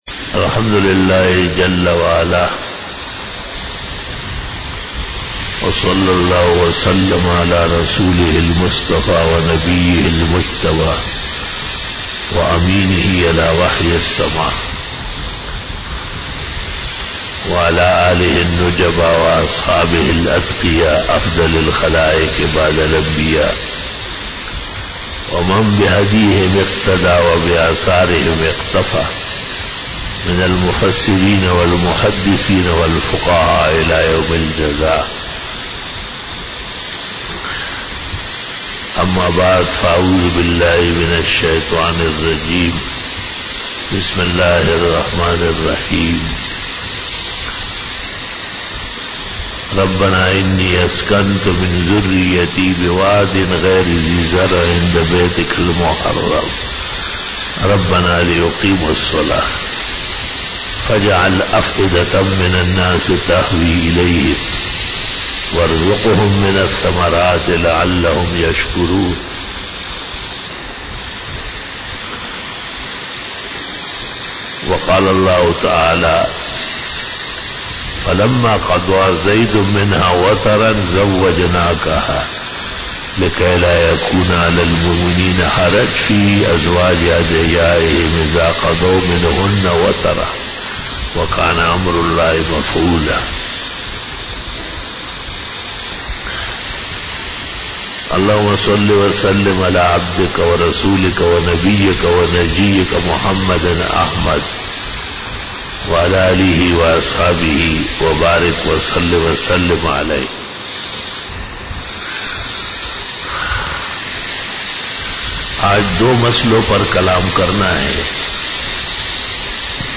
43 BAYAN E JUMA TUL MUBARAK (25 October 2019) (25 Safar 1441H)
Khitab-e-Jummah